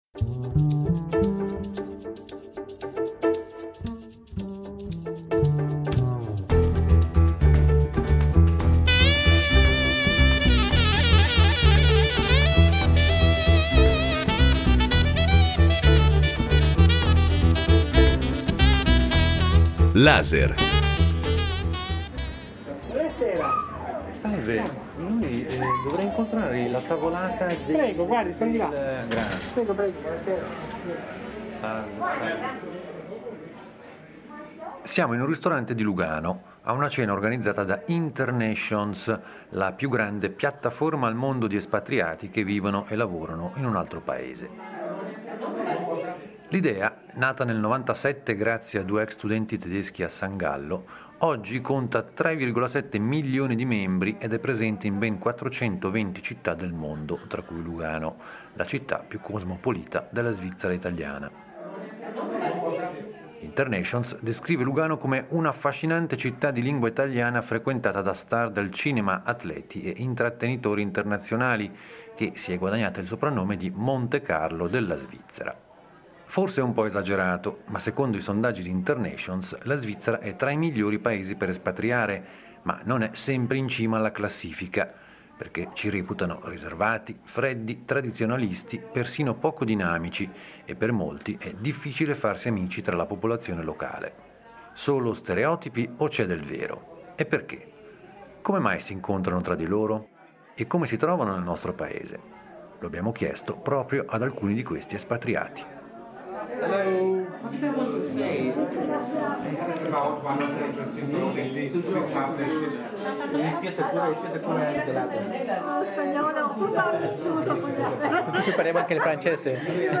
Per capire meglio il loro punto di vista (prima delle nuove misure Covid-19), ci siamo intrufolati a una cena di alcuni espatriati trapiantati a Lugano.